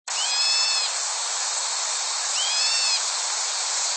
8-1金山紅嘴黑鵯喵1.mp3
紅嘴黑鵯(台灣亞種) Hypsipetes leucocephalus nigerrimus
錄音地點 新北市 金山區 金山
錄音環境 森林
行為描述 喵叫